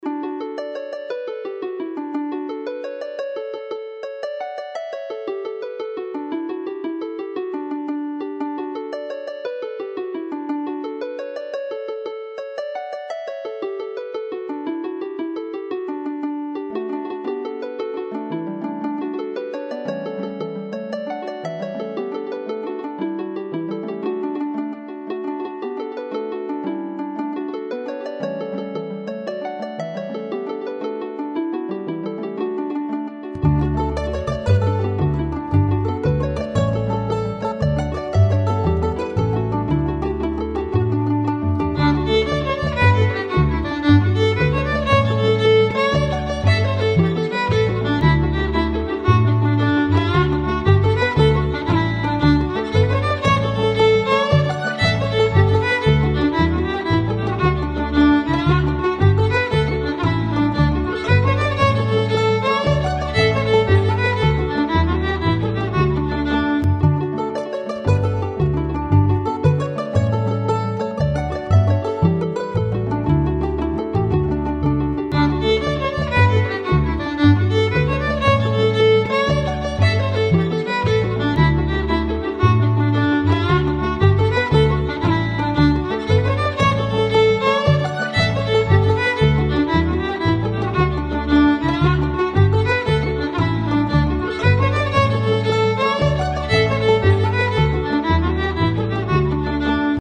N:It is a well established Irish jig.
R:Jig
M:6/8
K:D